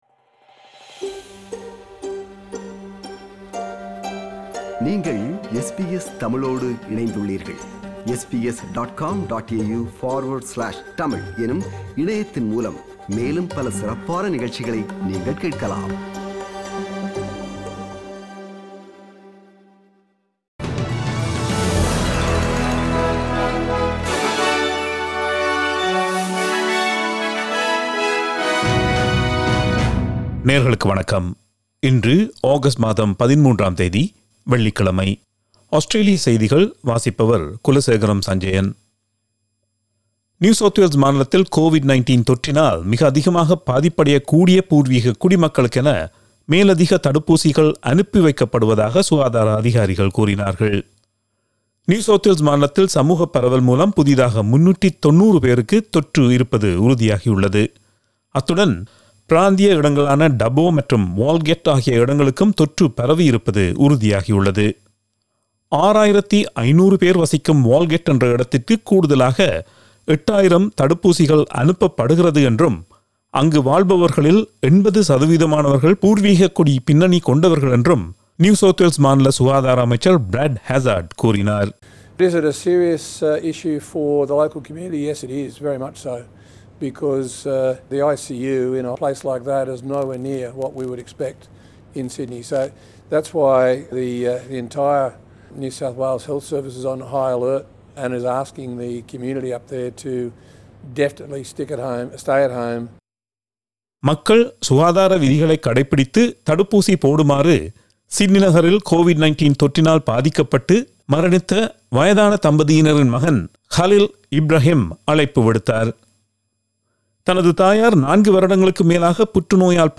Australian news bulletin for Friday 13 August 2021.